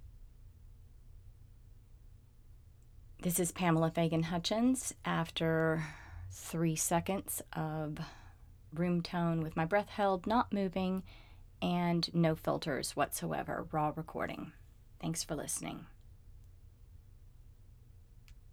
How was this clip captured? No filters, holding my breath, not moving, just room tone, then I start speaking at 3 seconds. Your raw performance is way too quiet.